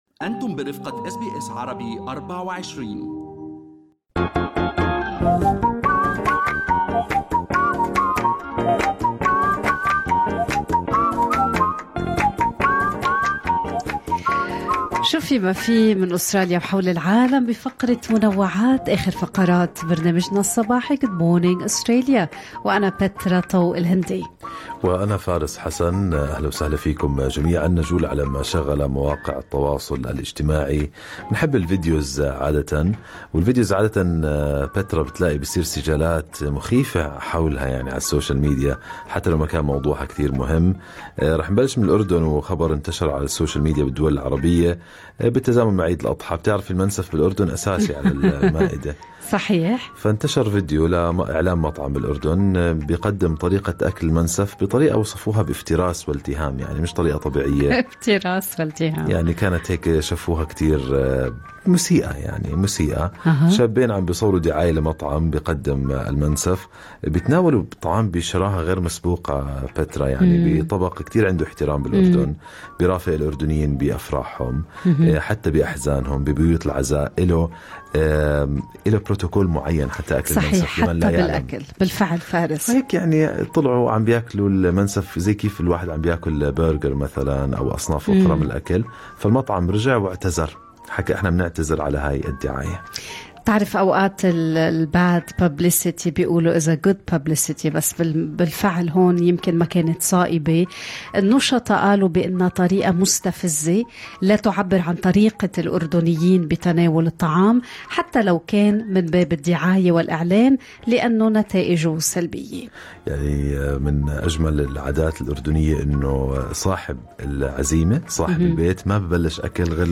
نقدم لكم فقرة المنوعات من برنامج Good Morning Australia التي تحمل إليكم بعض الأخبار والمواضيع الأكثر رواجا على مواقع التواصل الاجتماعي.